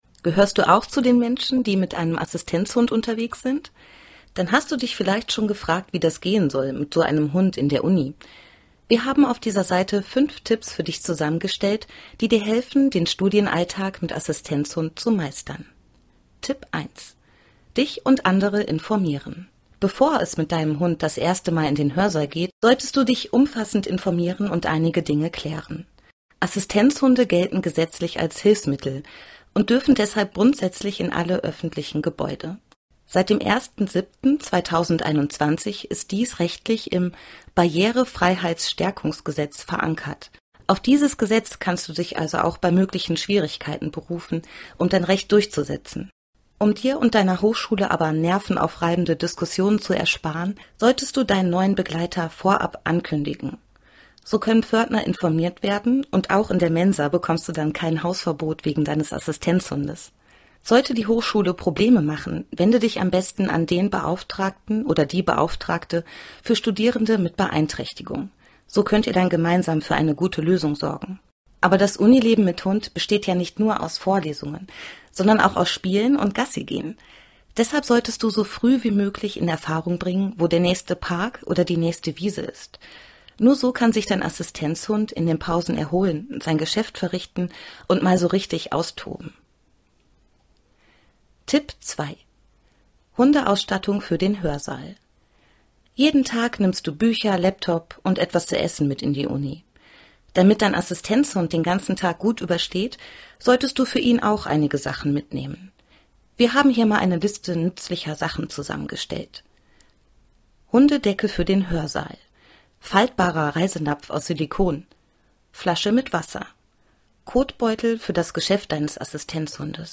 Kein Problem, wir haben sie für euch eingesprochen: